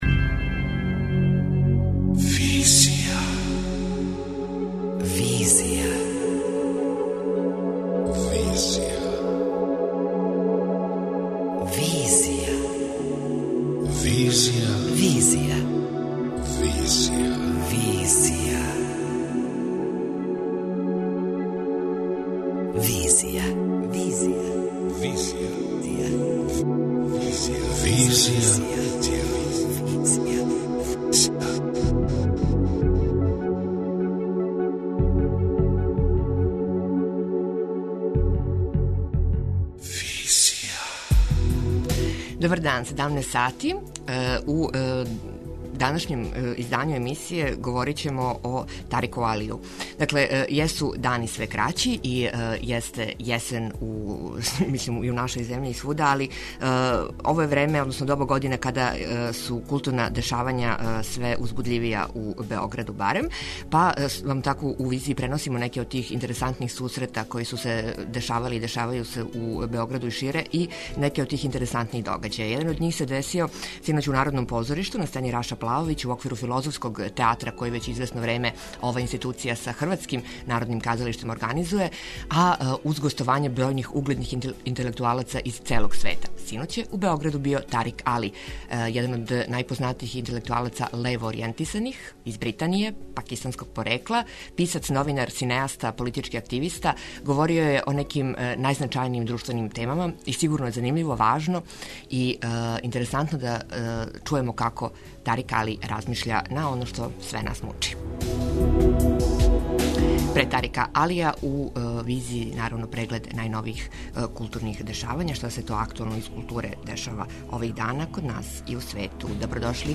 О неким од најактуелнијих тема данашњице говори Тарик Али, британски интелектуалац пакистанског порекла, писац, новинар, синеаста, политички активиста.